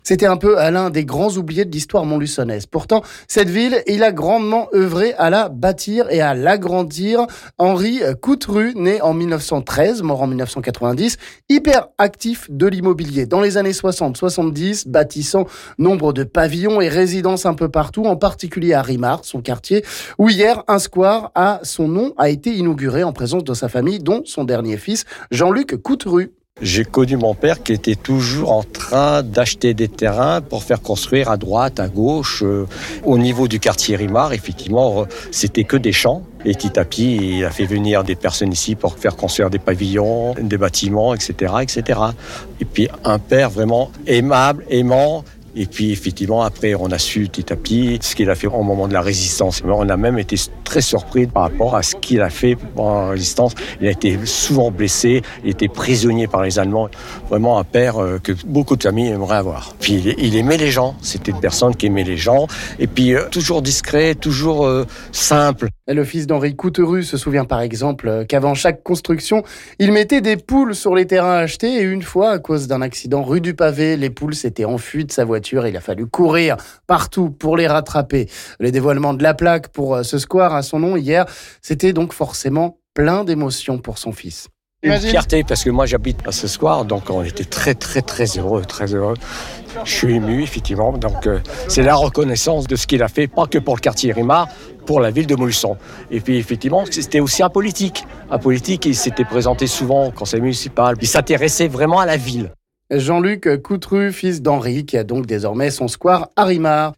On écoute le témoignage